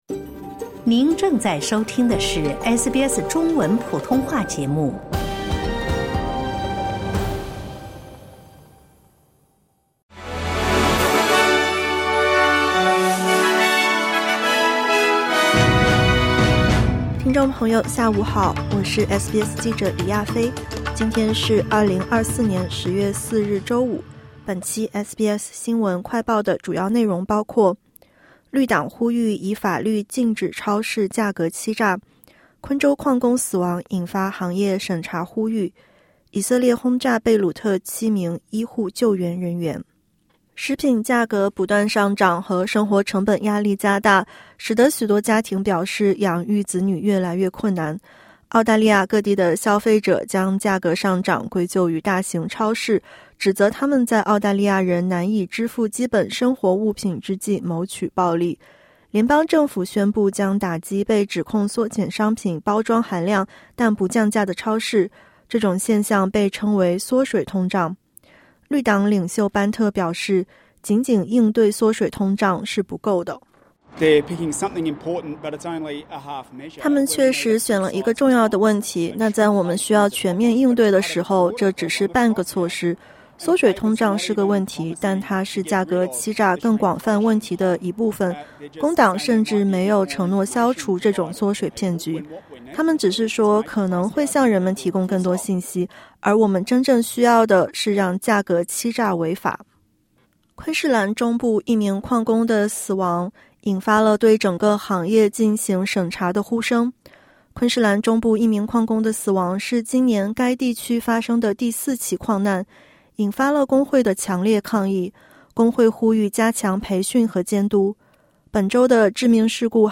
【SBS新闻快报】绿党呼吁以法律制止超市价格欺诈